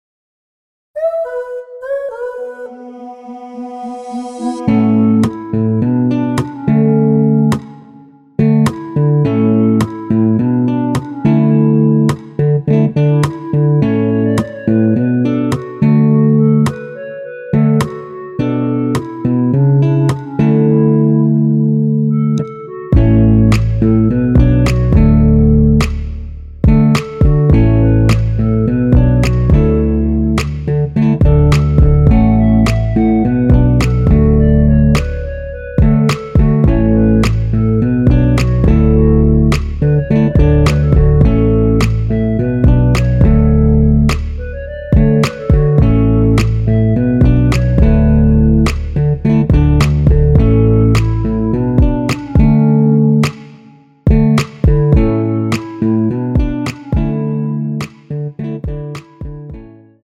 원키에서(-2)내린 멜로디 포함된 MR입니다.(미리듣기 확인)
앞부분30초, 뒷부분30초씩 편집해서 올려 드리고 있습니다.
중간에 음이 끈어지고 다시 나오는 이유는